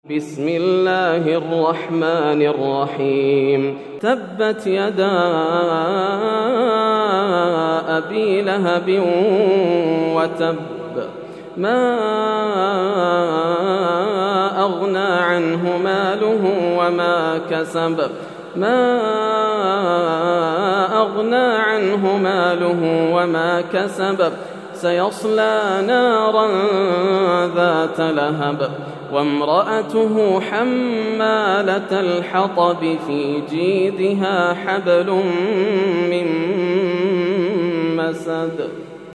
سورة المسد > السور المكتملة > رمضان 1431هـ > التراويح - تلاوات ياسر الدوسري